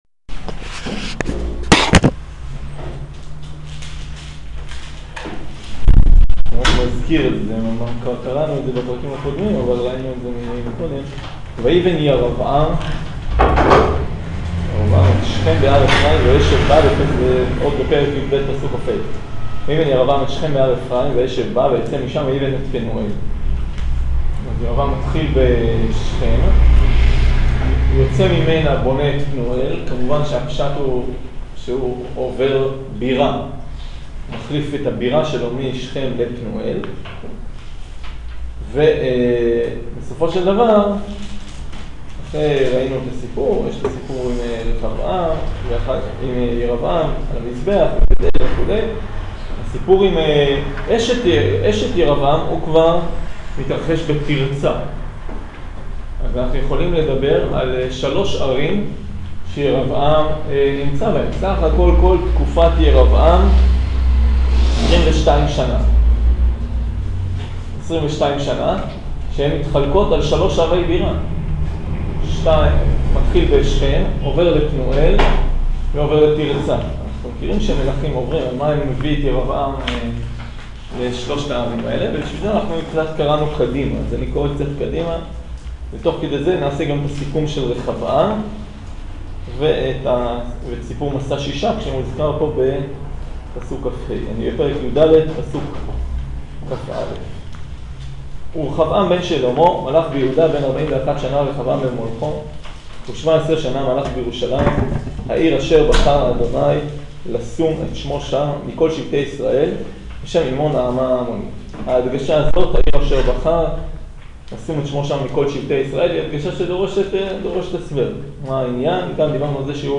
שיעור סוף פרק יד